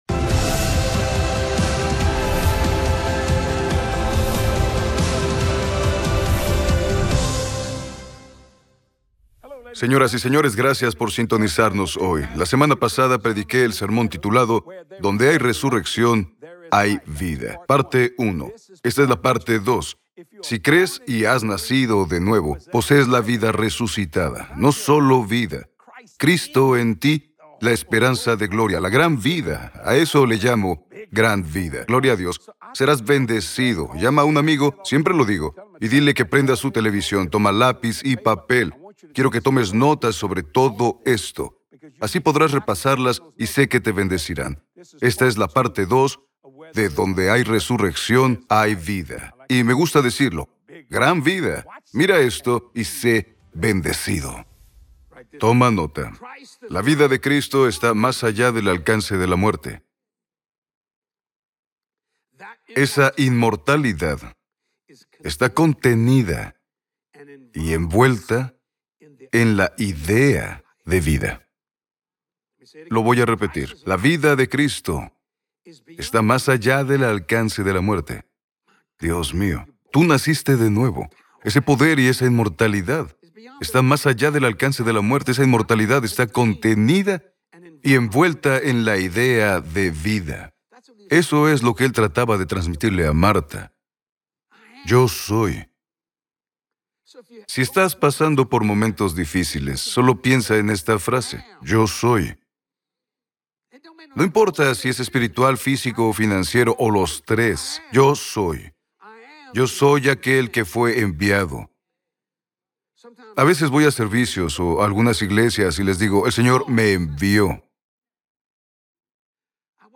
mensaje de Pascua